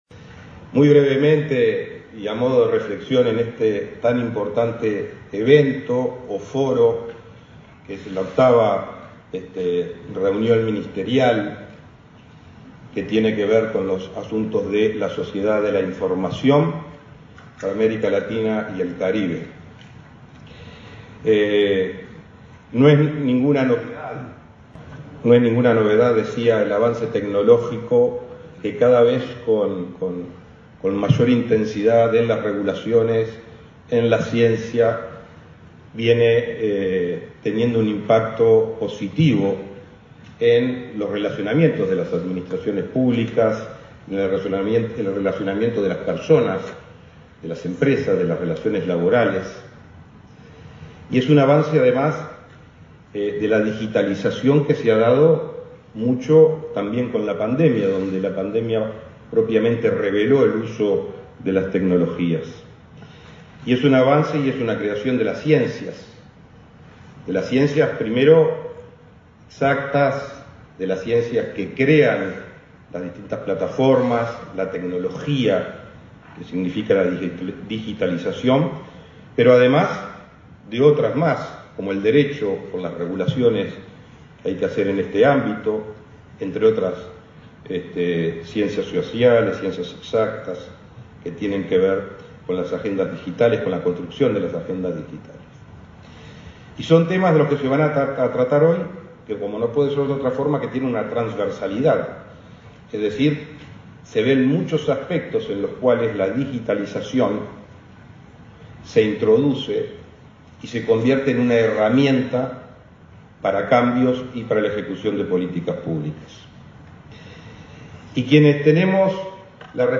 Palabras de autoridades en conferencia sobre sociedad de la información